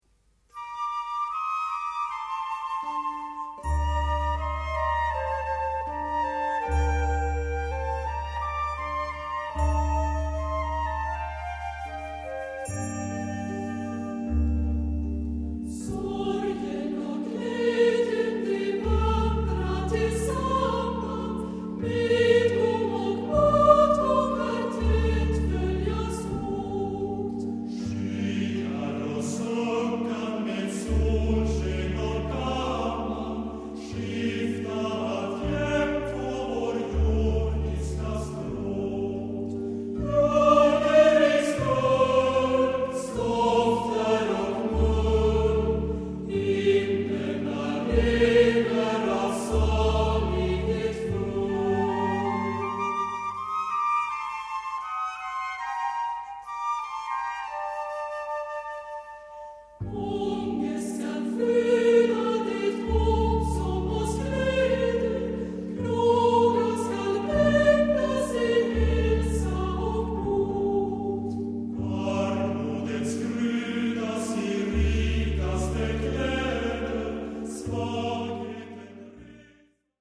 ★ 安定祥和、莊嚴聖潔的人聲合唱讓您暫離塵囂，百聽不厭！
★ 北國瑞典天使歌聲，獨特純淨空靈意境、音色柔軟綿密、通透清晰！